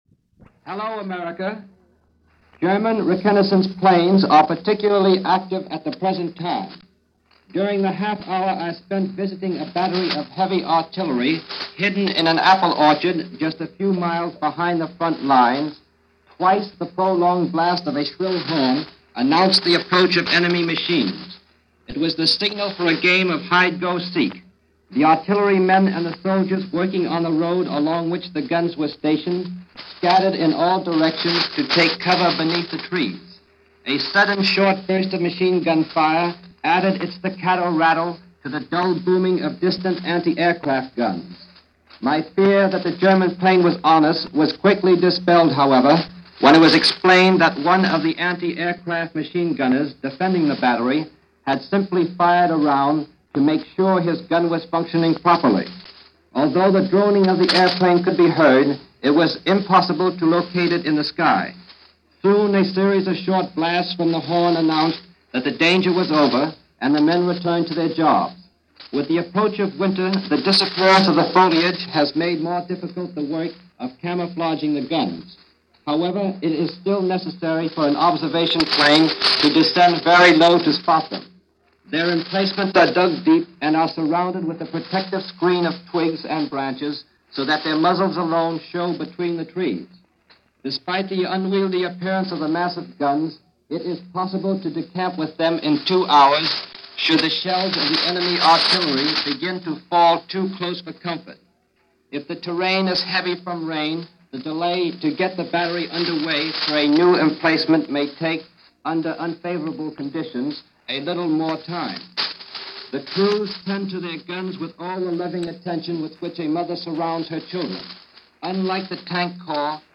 News reports from Europe on the preparations and reactions to the war.
And this report took place during one of those black-out drills at 5 in the morning on November 24th.